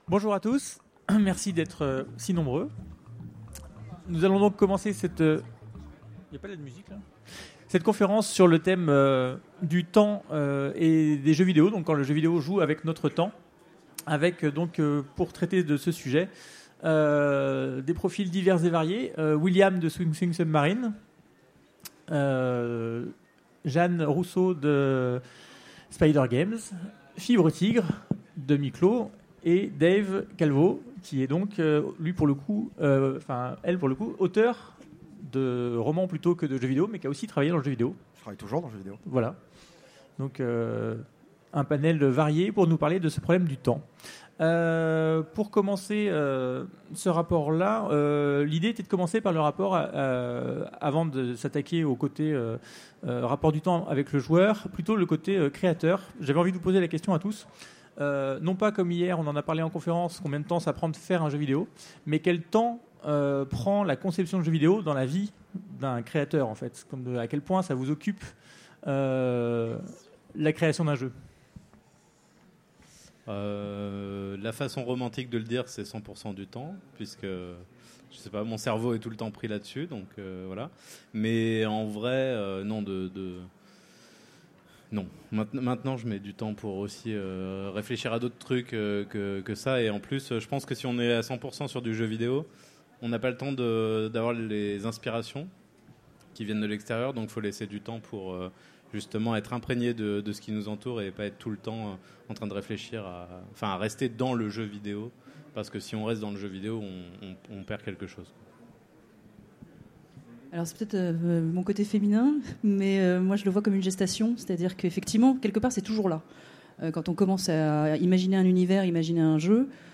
Utopiales 2017 : Conférence Quand le jeu vidéo joue avec notre temps